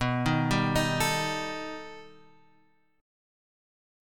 B Augmented 7th